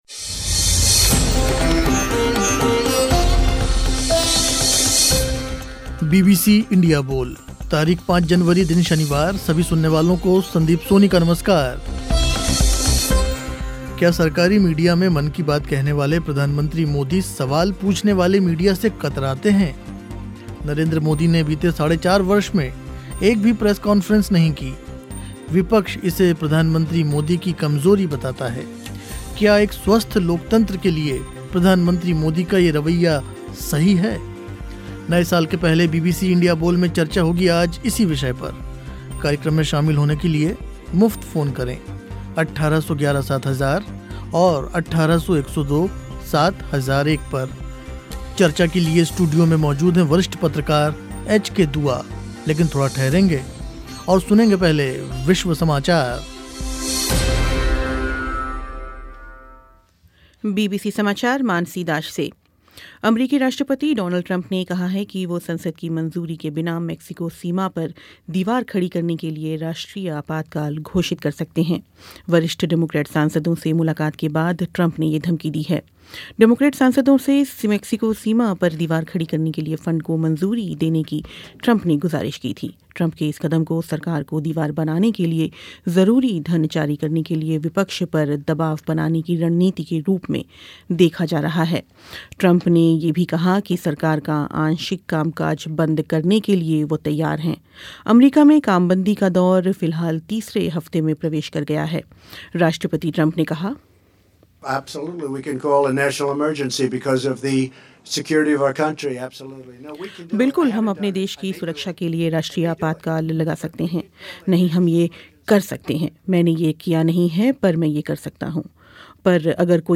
स्टूडियो में मौजूद थे वरिष्ठ पत्रकार एच.के. दुआ.